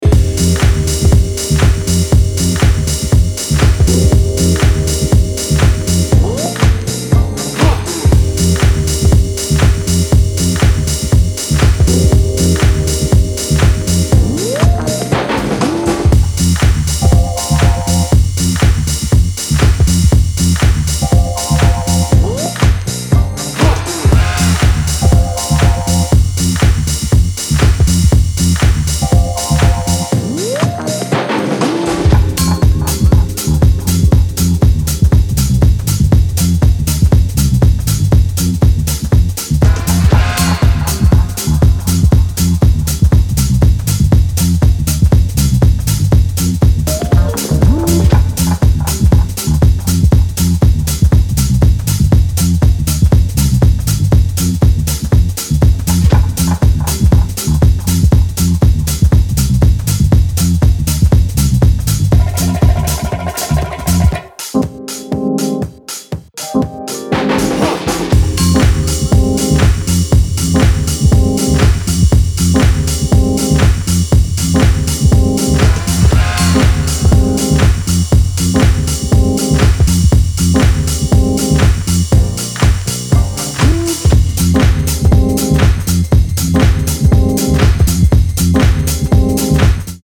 新旧のハウス・ファンに手放しで推せる、充実の内容です。